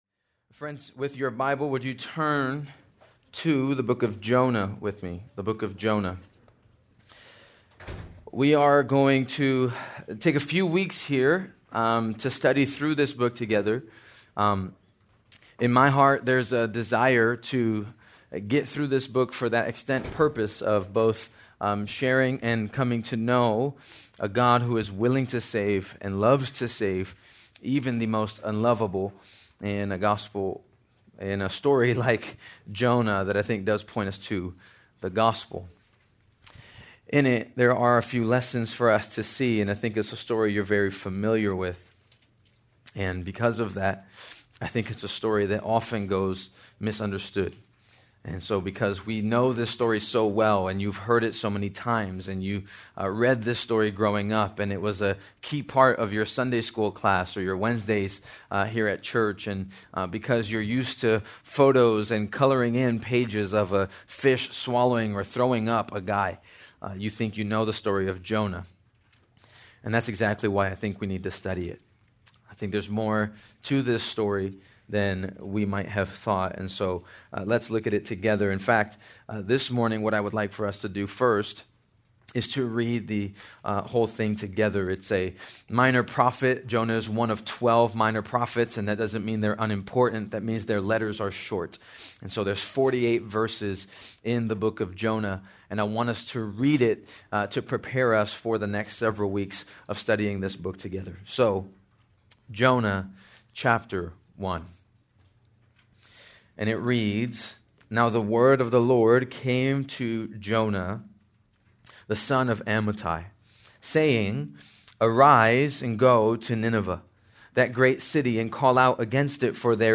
March 23, 2025 - Sermon | 180 Ministry | Grace Community Church